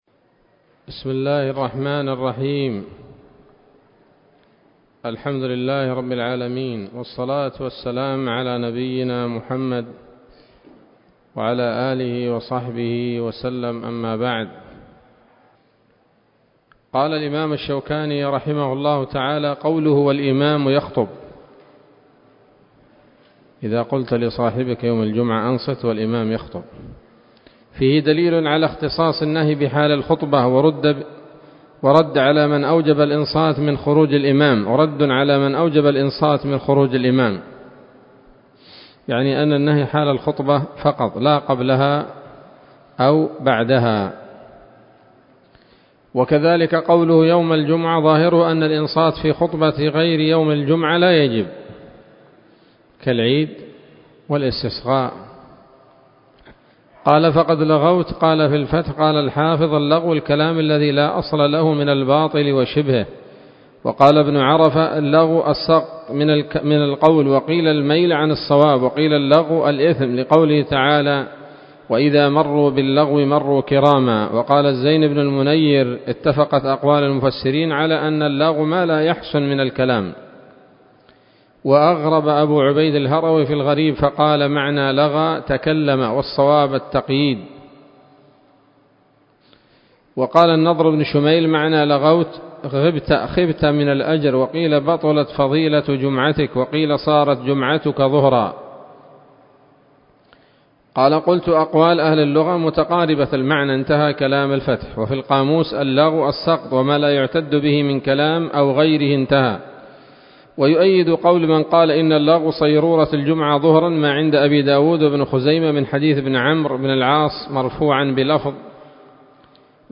الدرس الحادي والثلاثون من ‌‌‌‌أَبْوَاب الجمعة من نيل الأوطار